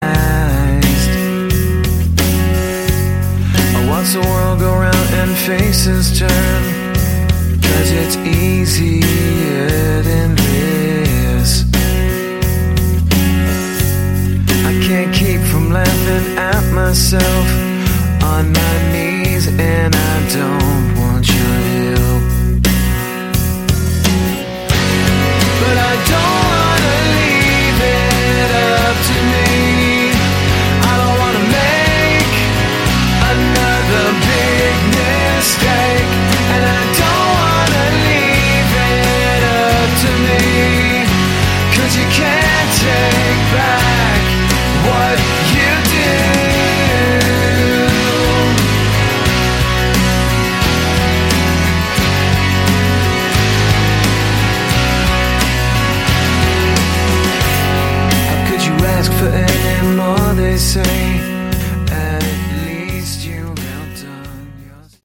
Category: Melodic Rock
Lead vocals, Bass, Guitars, Drums
Guitar solos